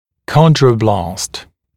[‘kɔndrə(u)blɑːst][‘кондро(у)бла:ст]хондробласт, хрящеобразующая клетка